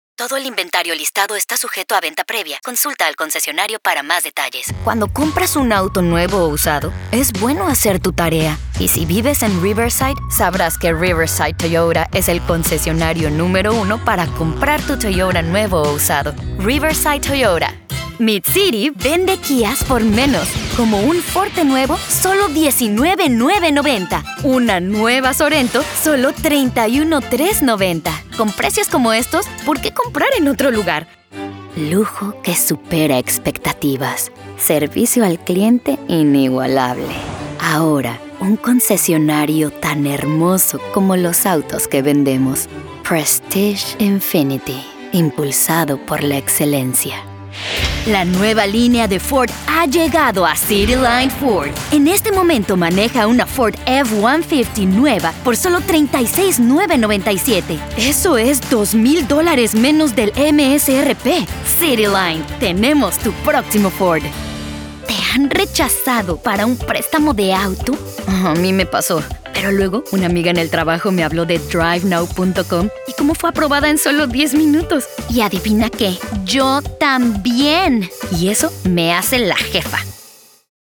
Automotive
Mexican